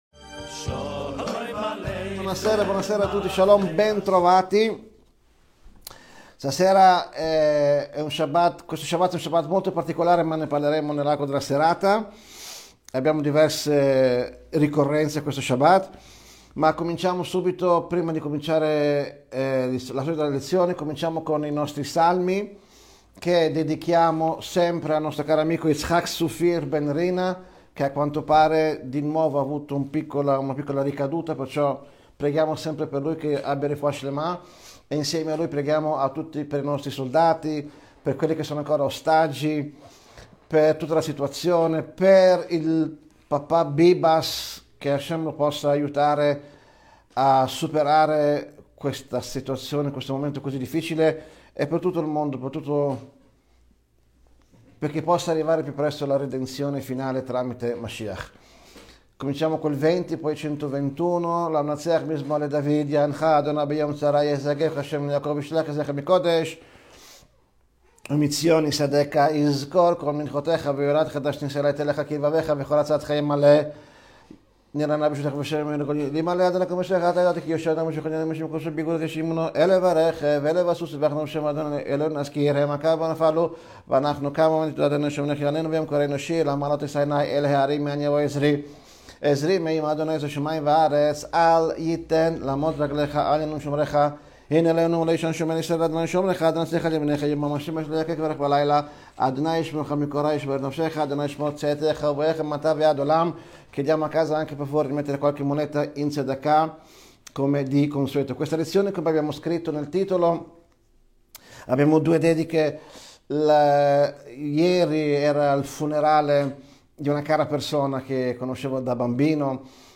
Lezione del 27 febbraio 2025